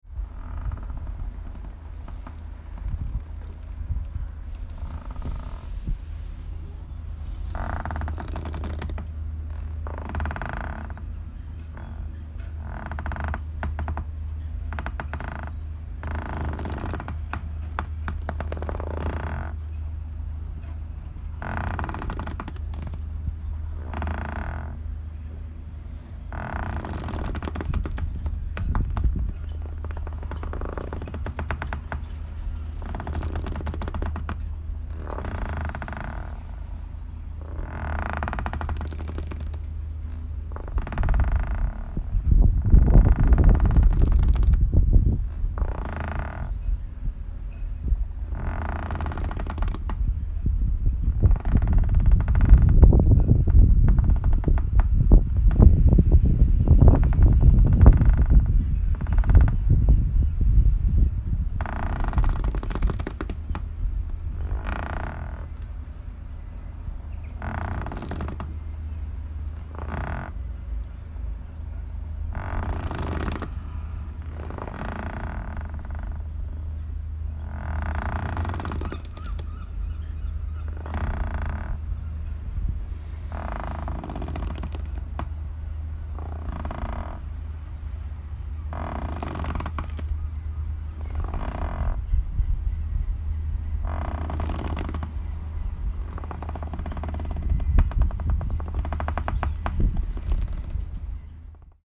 Sounds from home (elsewhere) are overlaid and geo-located along the canal.
Buoy, Boat, Wooden Jetty
buoyBoatWoodenJetty.mp3